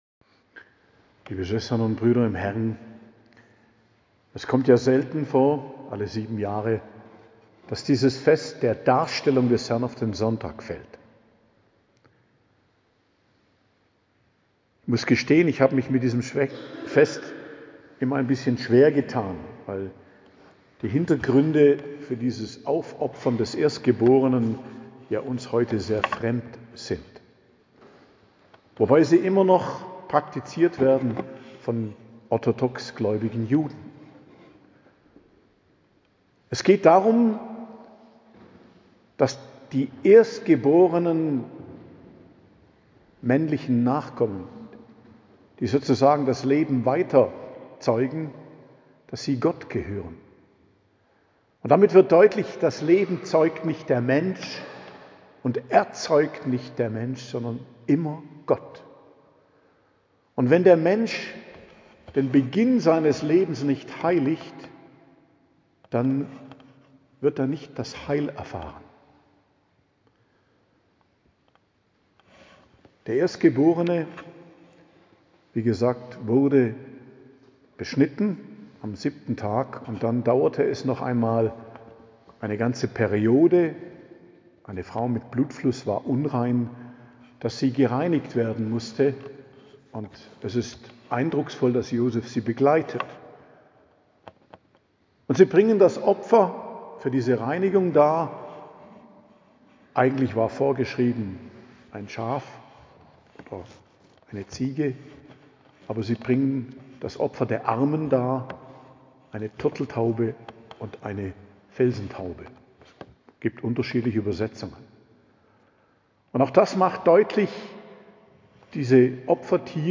Predigt am Fest Darstellung des Herrn - Mariä Lichtmess, 2.02.2025 ~ Geistliches Zentrum Kloster Heiligkreuztal Podcast